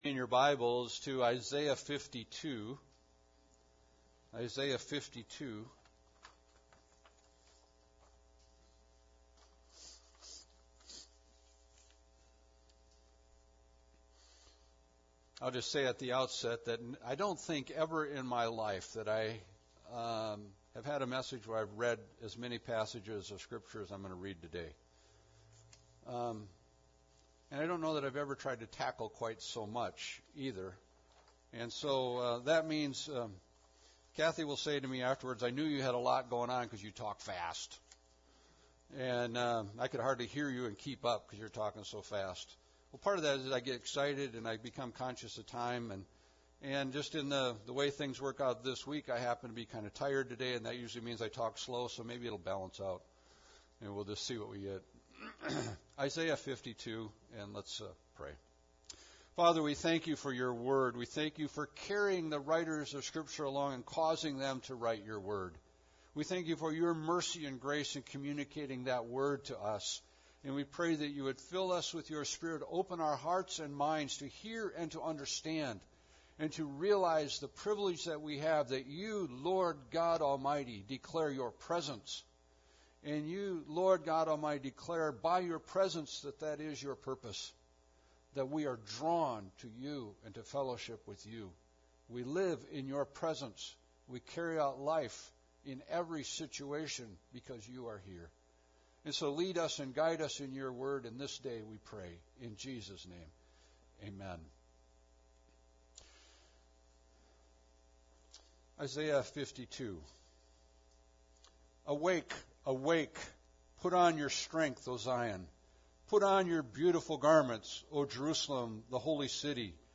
Passage: Isaiah 52:1-15 Service Type: Sunday Service